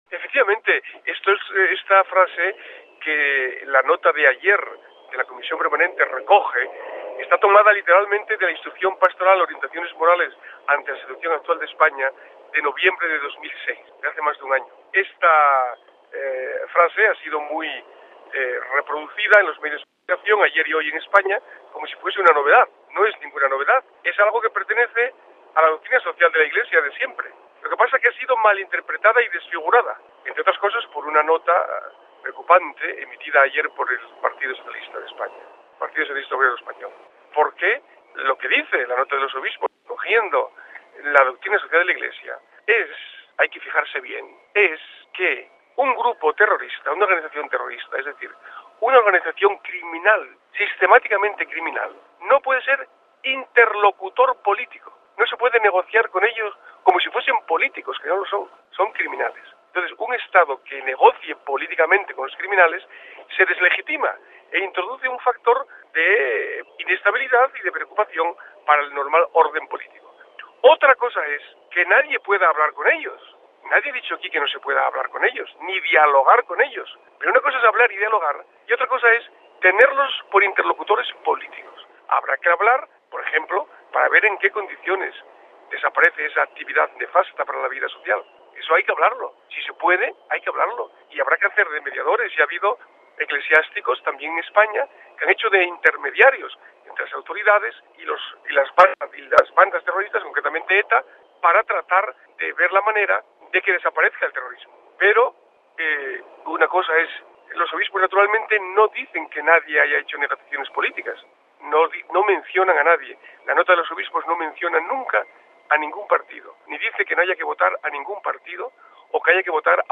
Para finalizar el secretario General de la conferencia Episcopal Española, el Obispo Juan Antonio Martínez Camino nos habla del fenómeno del terrorismo abordado en la nota por los Prelados españoles: RealAudio